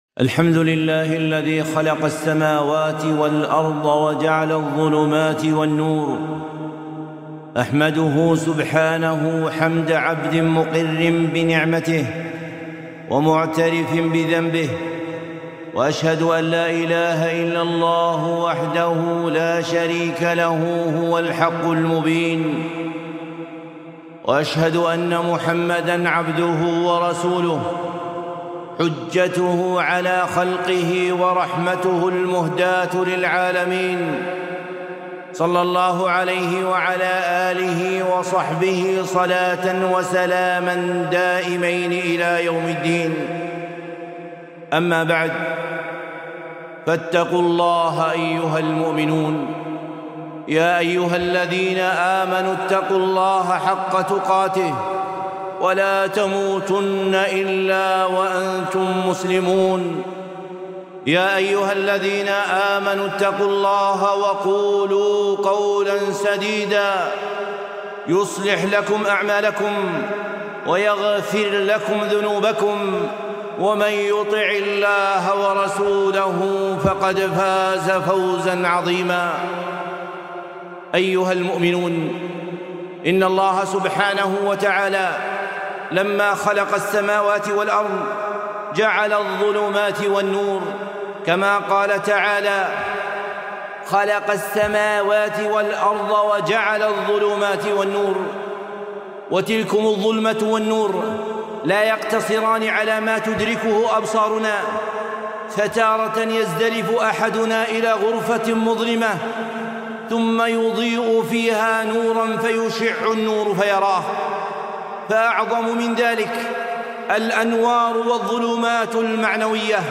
خطبة - الظلمات والنور